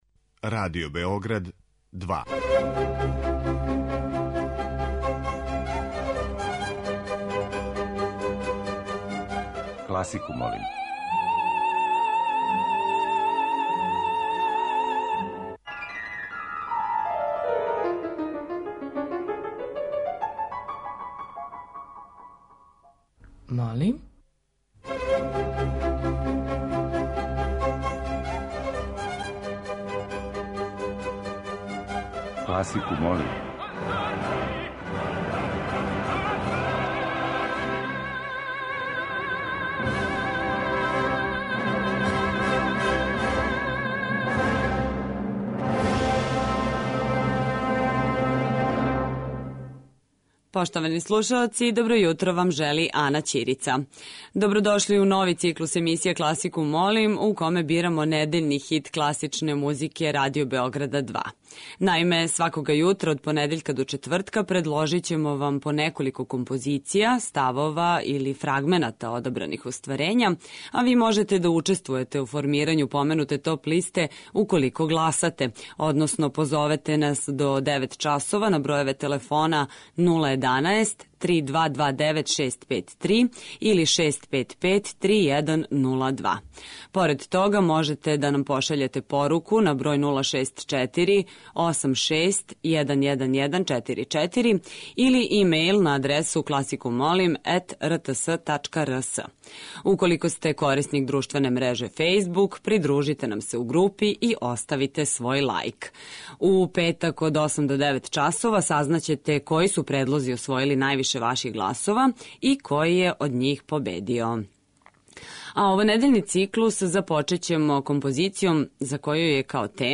Листа предлога за топ-листу класичне музике Радио Београда 2 ове седмице је обједињена снимцима са 51. фестивала 'Мокрањчеви дани', који је одржан од 9. до 14. септембра у Неготину.
Уживо вођена емисија, окренута широком кругу љубитеља музике, разноврсног је садржаја, који се огледа у подједнакој заступљености свих музичких стилова, епоха и жанрова.